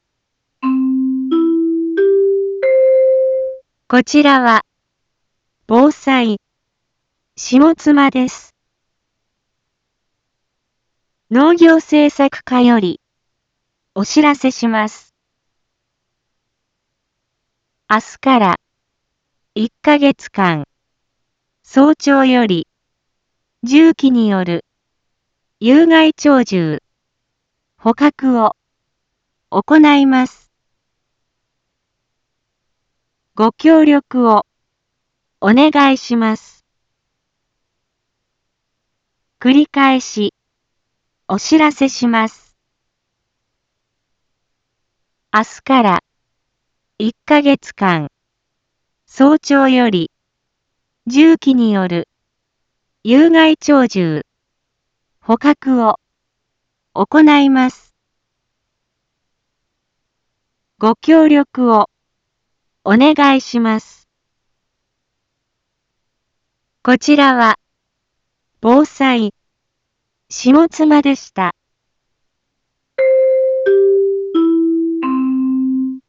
一般放送情報
Back Home 一般放送情報 音声放送 再生 一般放送情報 登録日時：2023-05-26 07:11:18 タイトル：有害鳥獣捕獲の実施について（千代川） インフォメーション：こちらは、防災、下妻です。